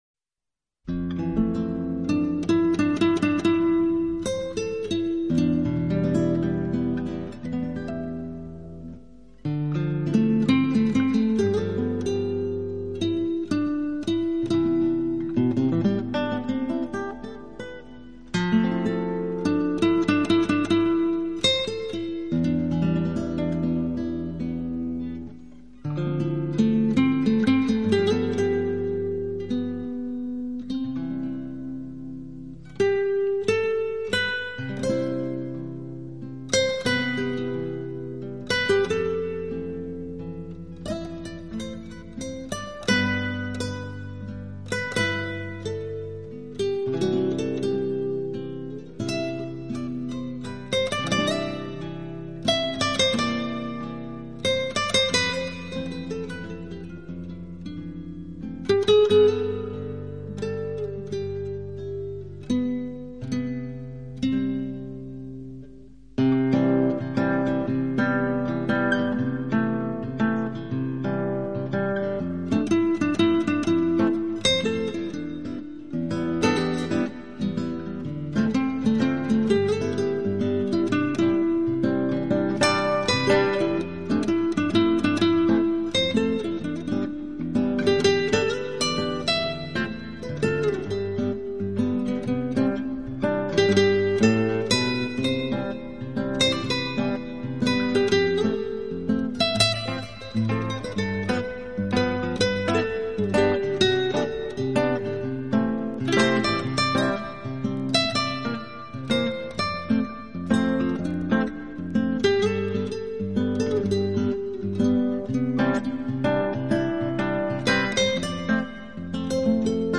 Genre: Instrumental
Quality: MP3 / Joint Stereo
专辑音色清脆动人且温馨旖丽，不禁展示了精彩绝伦的空间感，而且带出吉他音箱共鸣声的
浪漫吉他曲，经典西洋乐，音符似跳动的精灵，释放沉睡已久的浪漫情怀，用吉他的清脆表